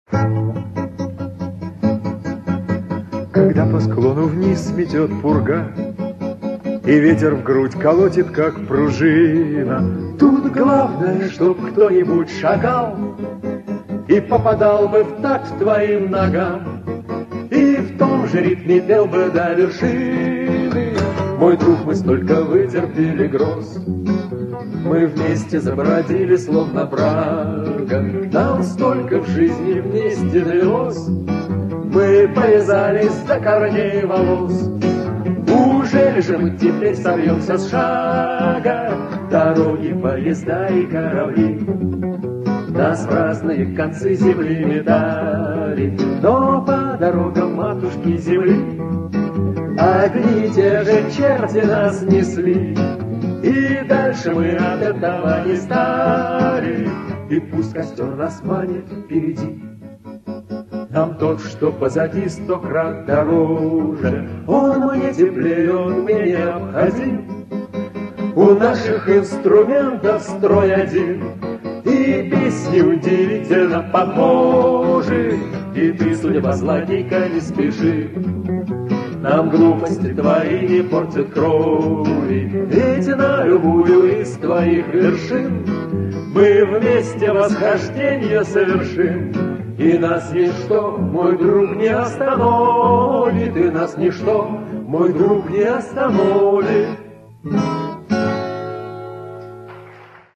МНЕ ТОЖЕ ТОЛЬКО ДУЭТ ПОПАДАЛСЯ, К СОЖАЛЕНИЮ